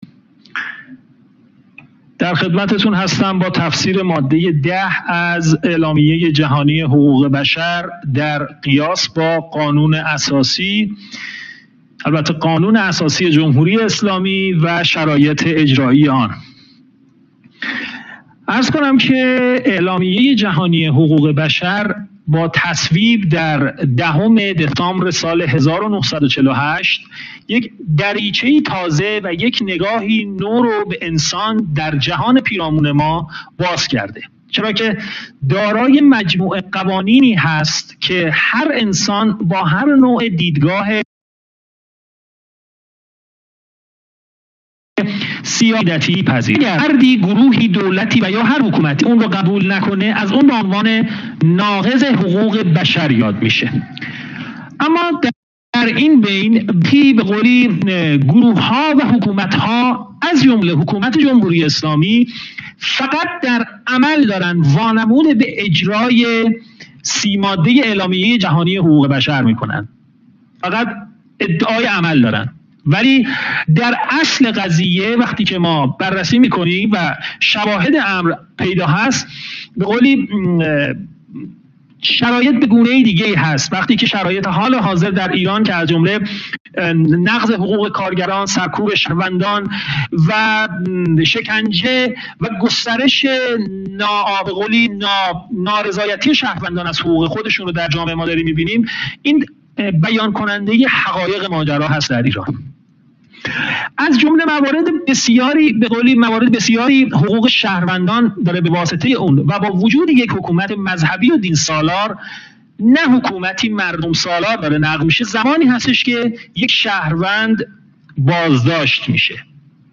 گزارش صوتی جلسه سخنرانی نمایندگی بادن ووتمبرگ و کمیته دفاع از حقوق پیروان ادیان